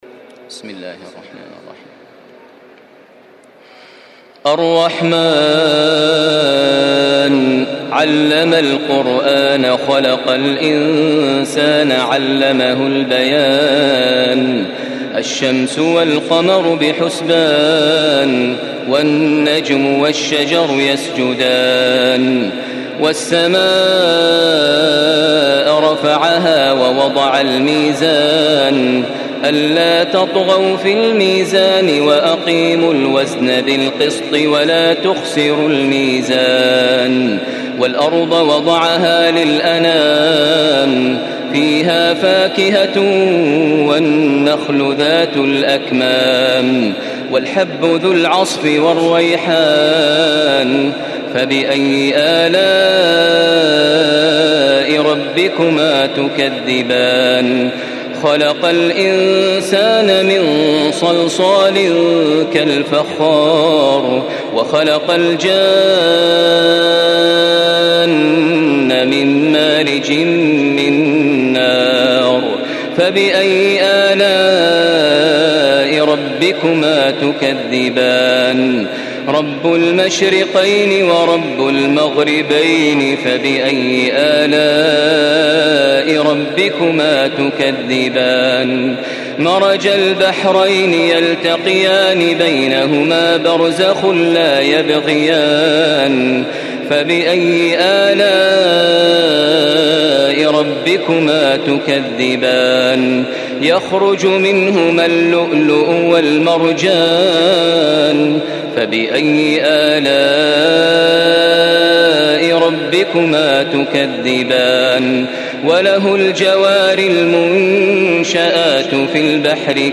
Surah Ar-Rahman MP3 by Makkah Taraweeh 1435 in Hafs An Asim narration.
Murattal Hafs An Asim